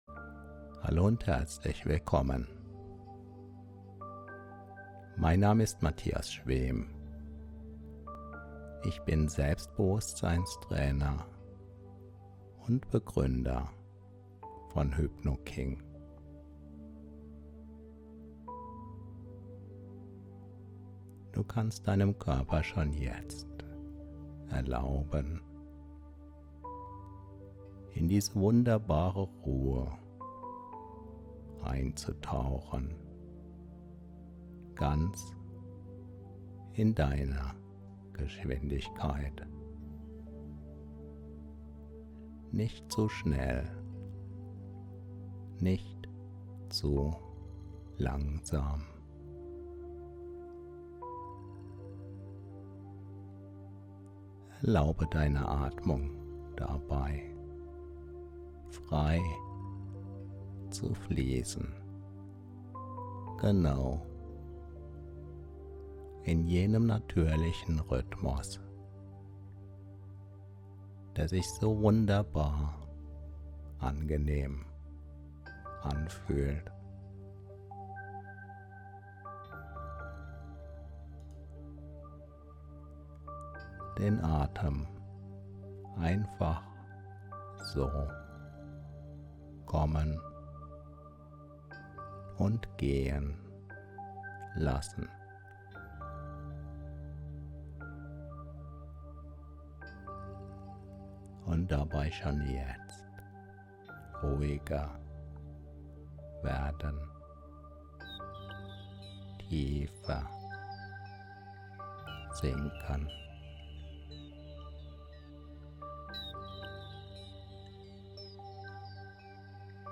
Dann lass diese kraftvolle Tiefschlafhypnose deine Rettung sein!
Sie ist sanft, effektiv und führt dich Schritt für Schritt in die Welt der Träume.